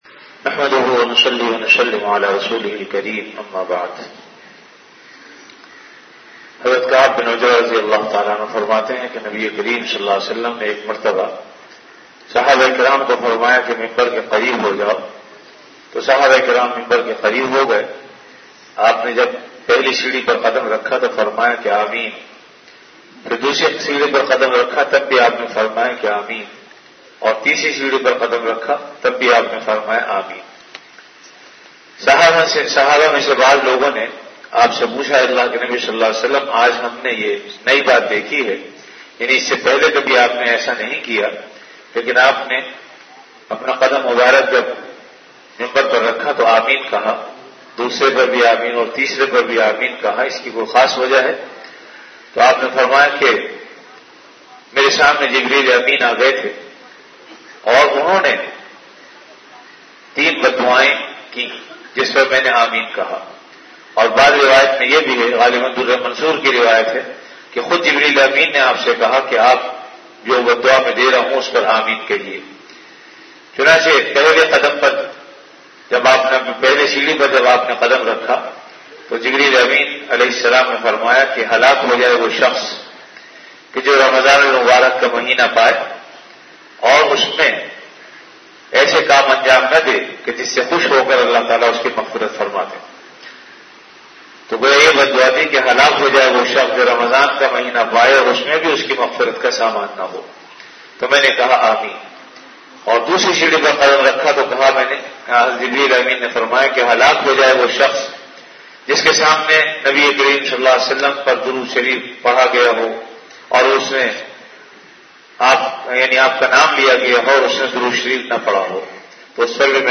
Delivered at Jamia Masjid Bait-ul-Mukkaram, Karachi.
Ramadan - Dars-e-Hadees · Jamia Masjid Bait-ul-Mukkaram, Karachi